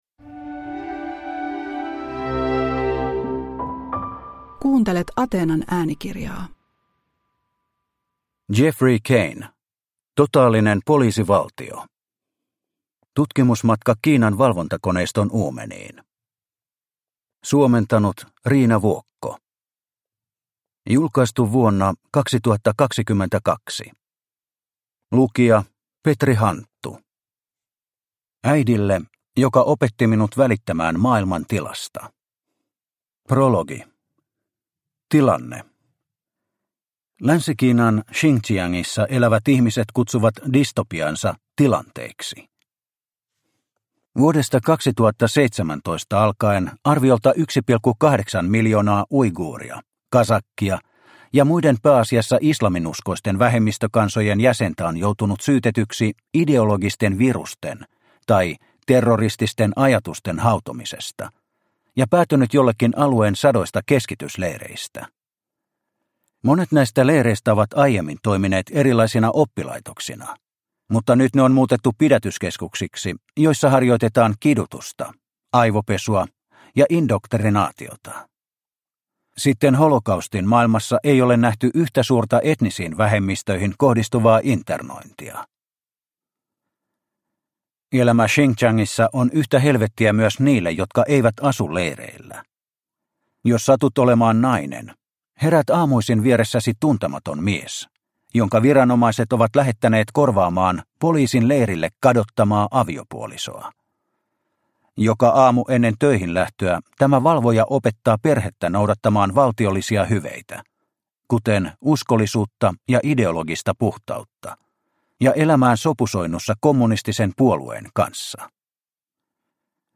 Totaalinen poliisivaltio – Ljudbok – Laddas ner